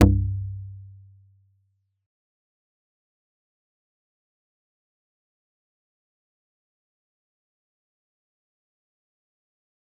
G_Kalimba-C2-mf.wav